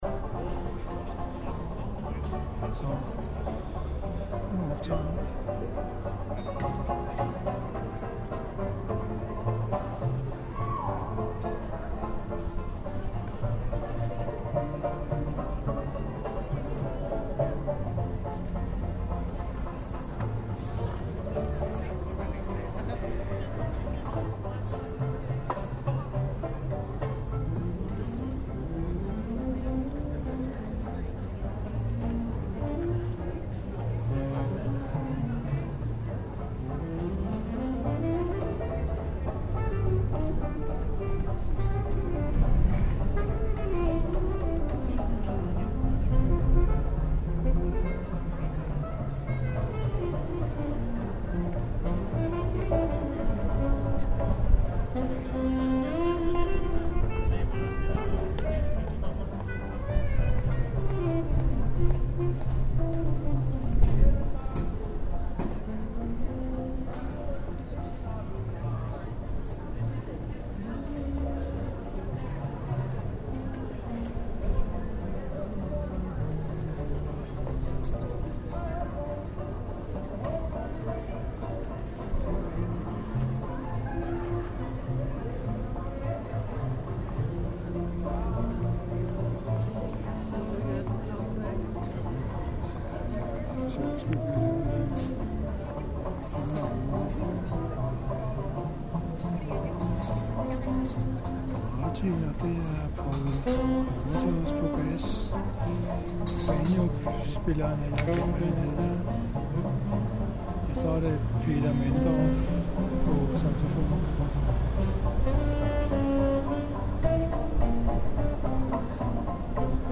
bas), ? (banjo
sax
Det var den 6. august på Kultorvet, hvor vi sad og nød en pils. Henne i den anden ende flettede Kultorvstrioen løs på den gamle evergreen "Margie".
et stemningsbillede fra dagen.